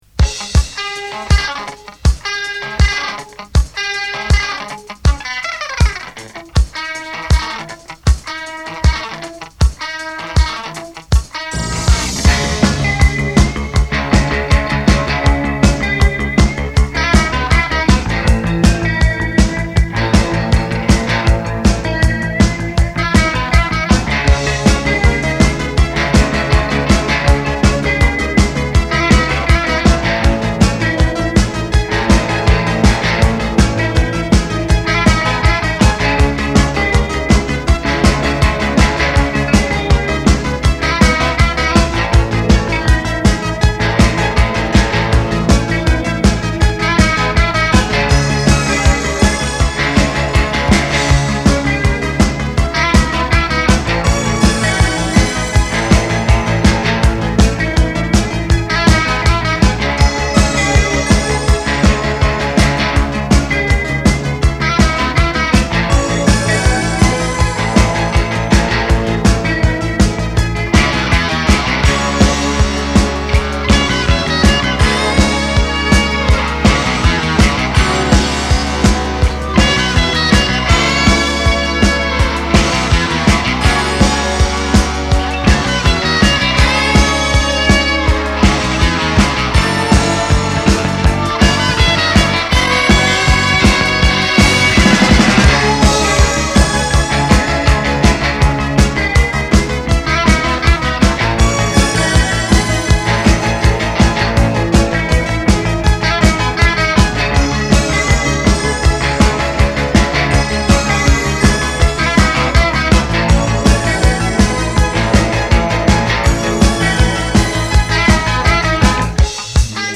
Gruppo Prog-Rock nato nel 1981 a Genova.